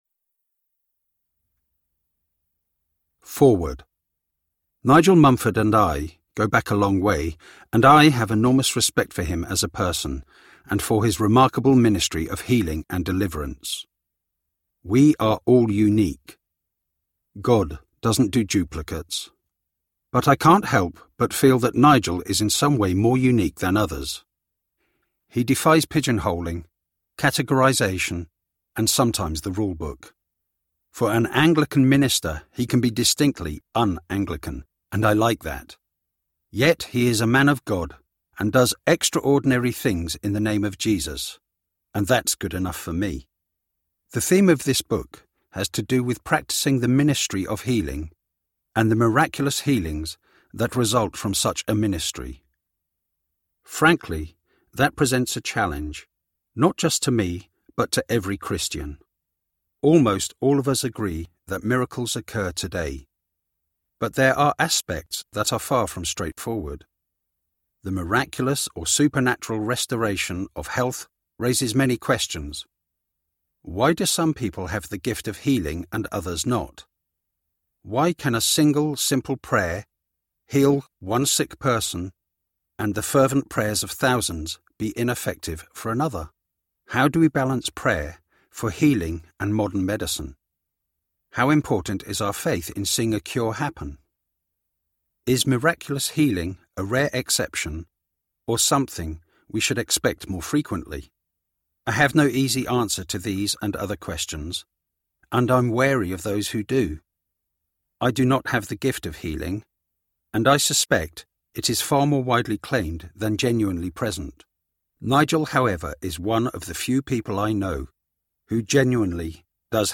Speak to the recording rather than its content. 7.4 Hrs. – Unabridged